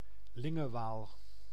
Lingewaal (Dutch pronunciation: [ˌlɪŋəˈʋaːl]